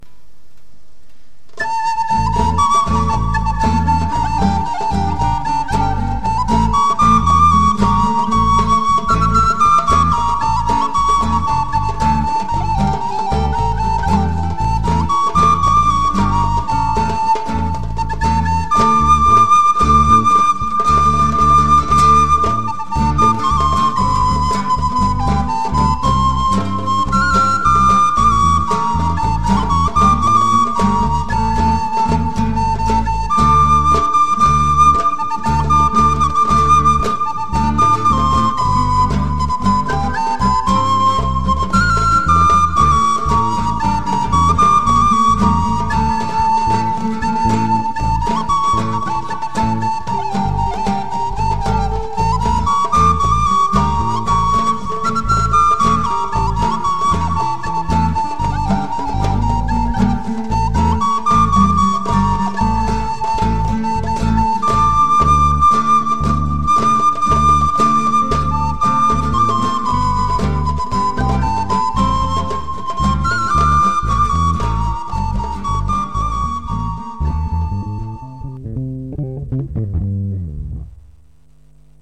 הוספתי ניגון על חיג'אז....